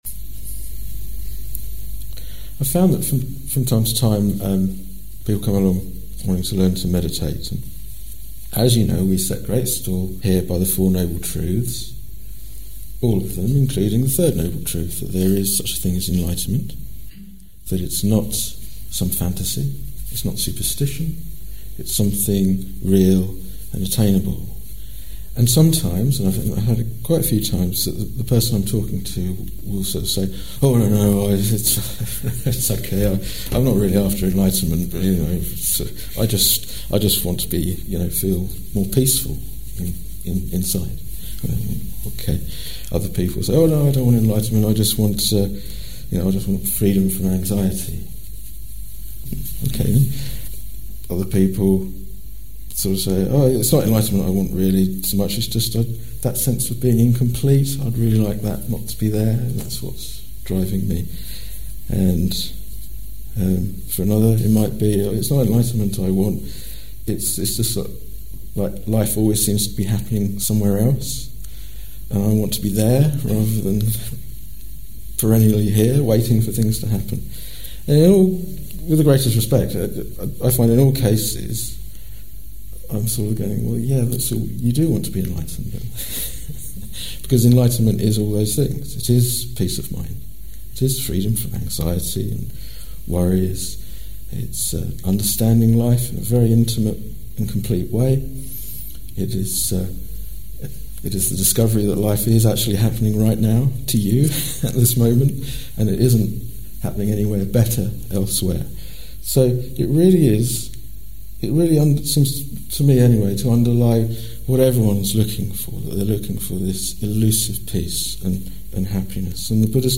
This talk was given in February 2014